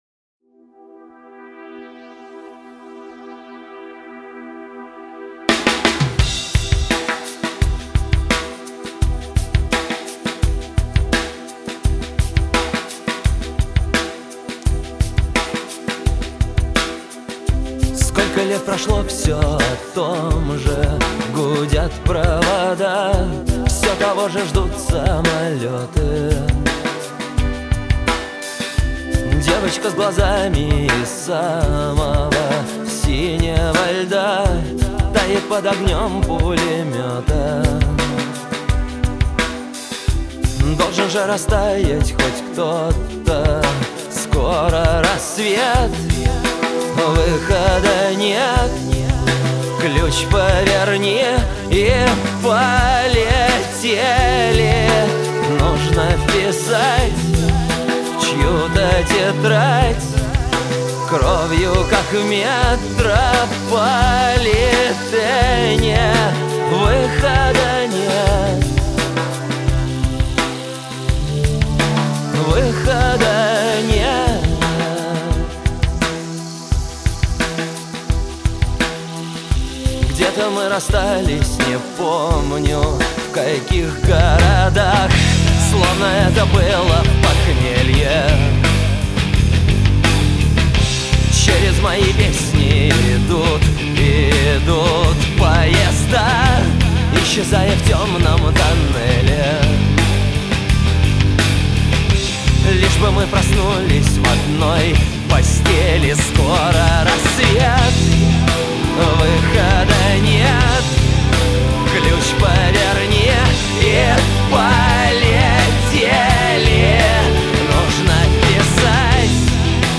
Рок музыка